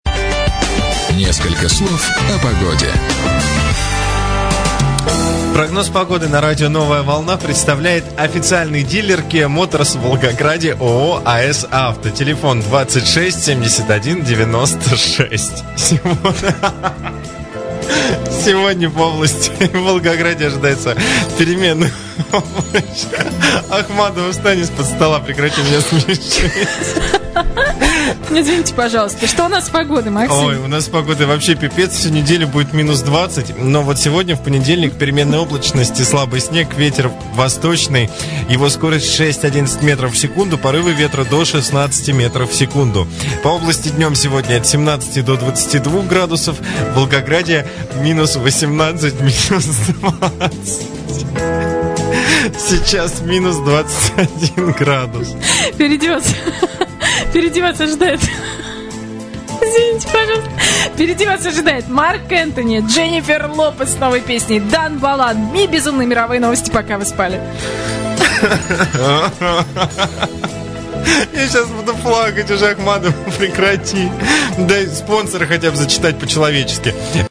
Прогноз погоды на "Новой Волне" (Волгоград). Запись эфира.
утреннее шоу
прогноз погоды